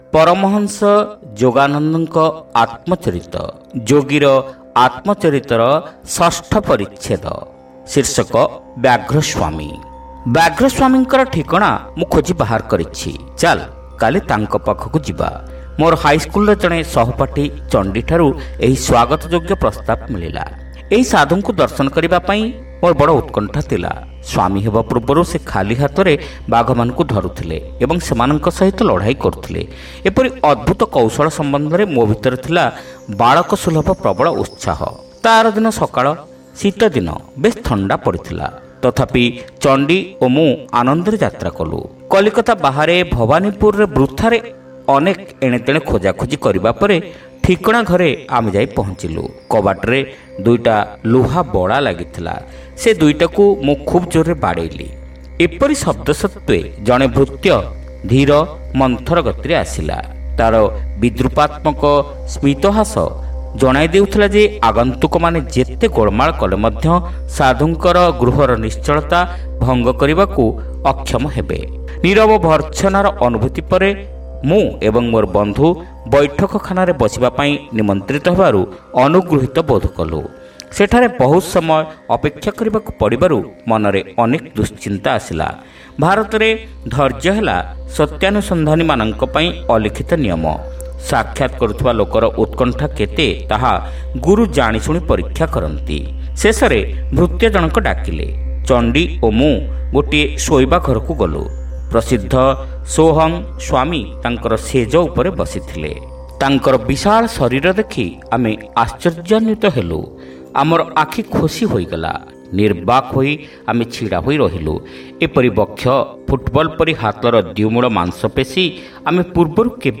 Audio Story : Byaghraswami - Yogira Atmacharita